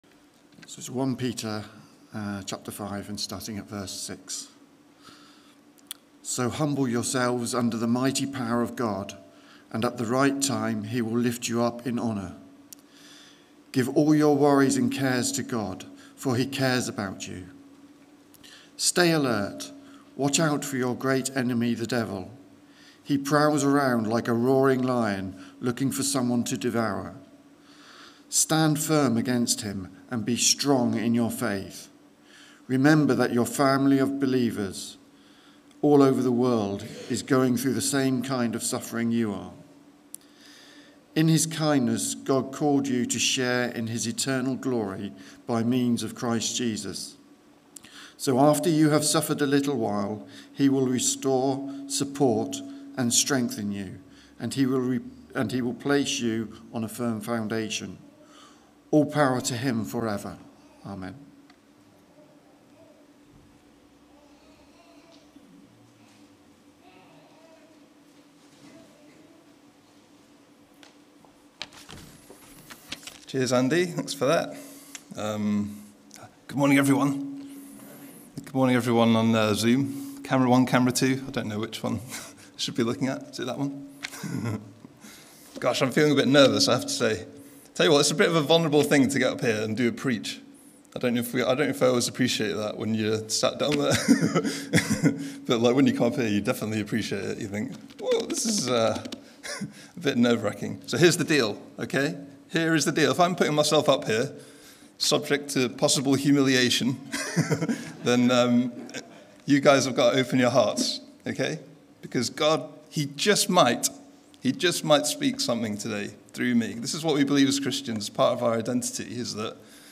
A talk from the series "Identity in Christ."